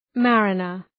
Shkrimi fonetik {‘mærənər}